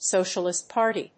アクセントSócialist Párty